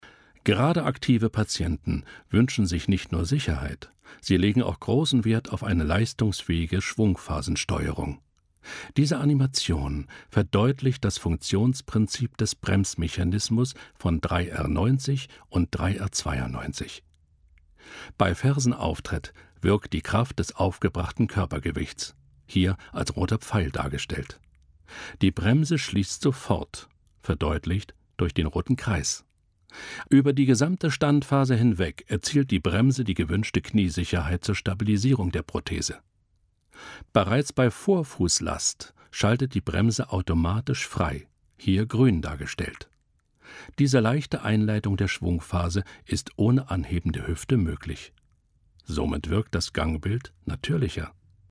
tiefe Stimme=WERBUNG:Bier,Bundeswehr, Ergo,Stadt München-DRAMA:Hörbuch, Kirchenlyrik-DOK:Jüdi. Museum-Leitstimme-COMIC:Paradiso-
Sprechprobe: Industrie (Muttersprache):